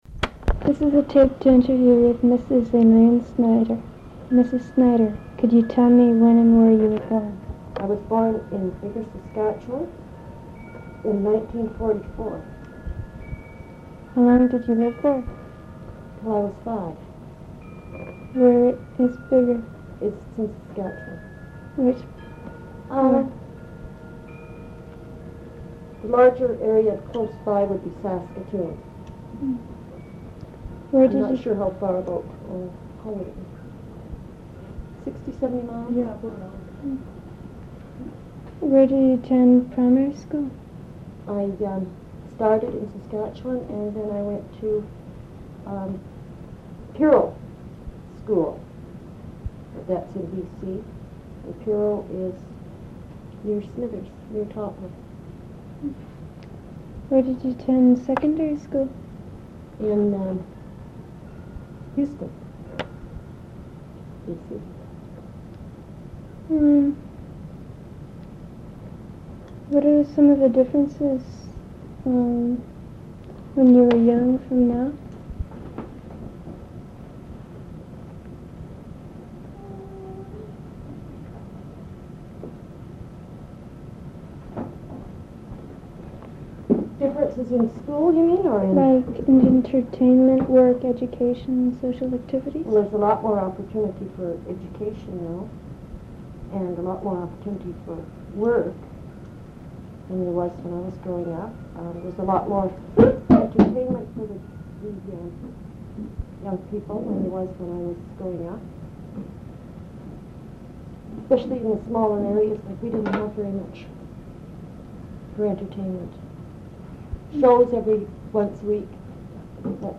Audio non-musical
oral histories (literary works)